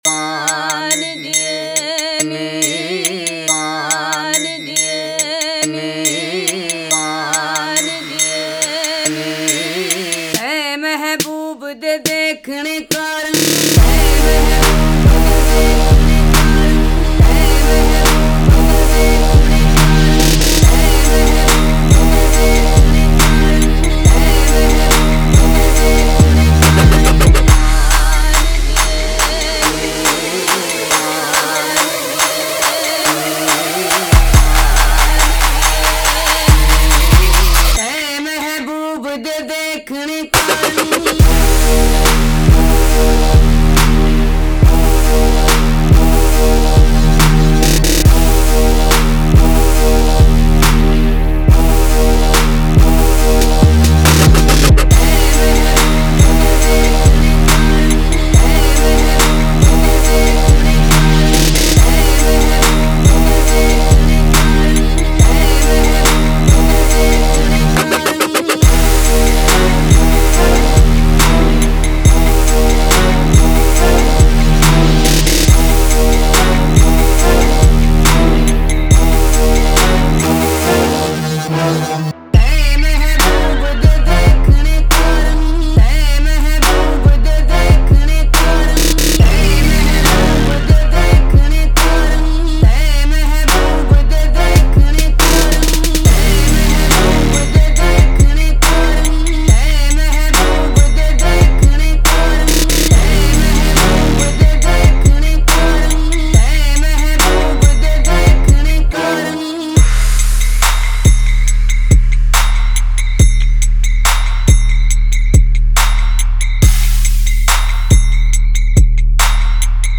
Зато классная ее ток на сабе слушать.
Тяжелая( В начало